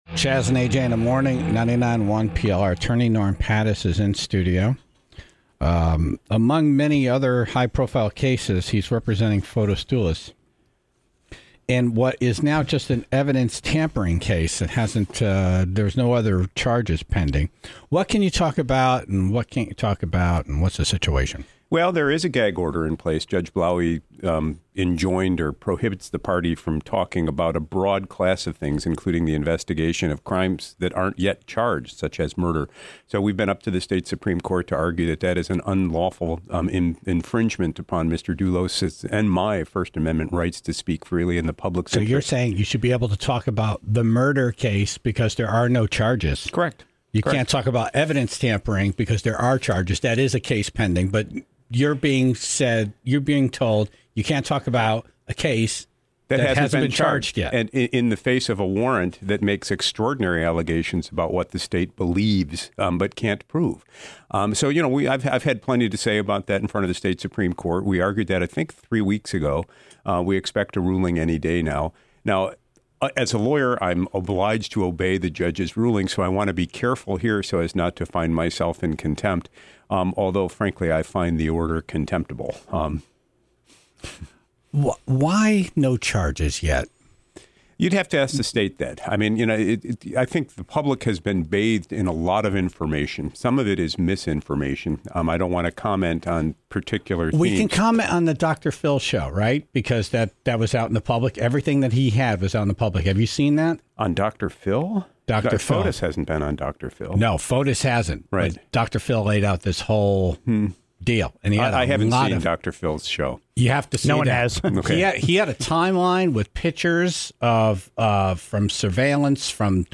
live in studio